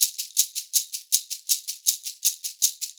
80 SHAK 15.wav